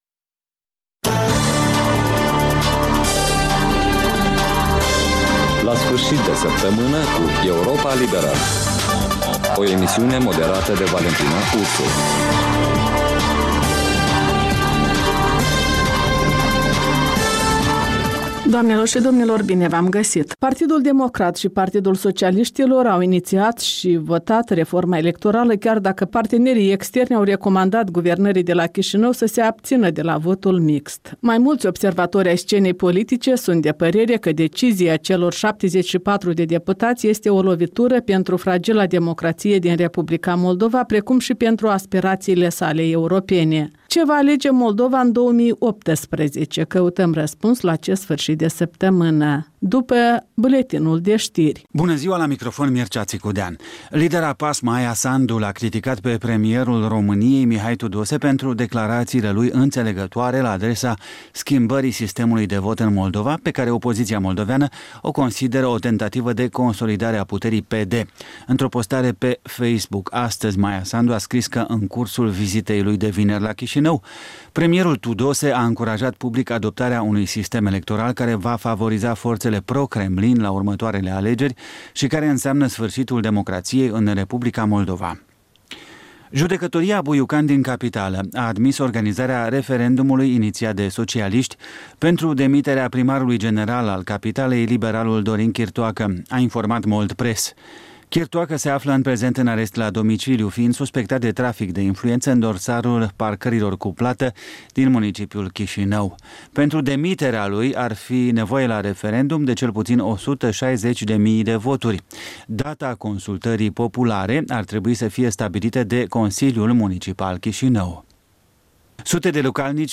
reportaje, interviuri, voci din ţară despre una din temele de actualitate ale săptămînii.